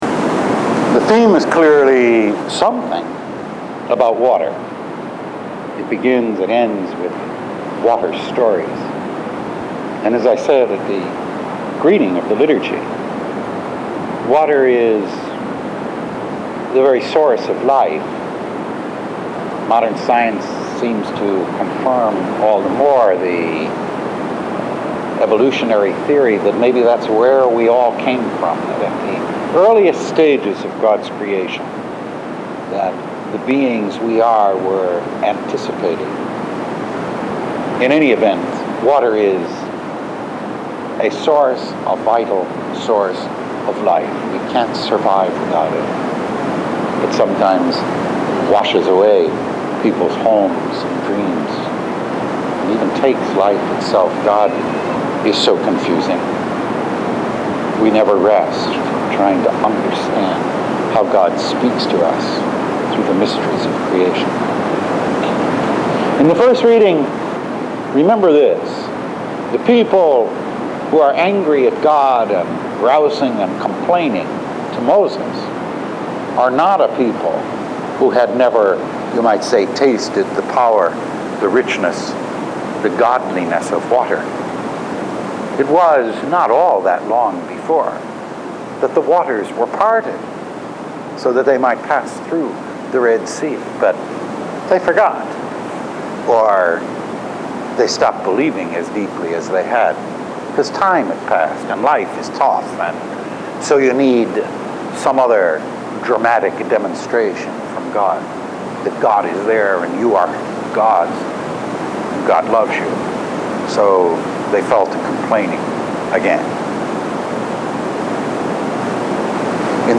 Faith « Weekly Homilies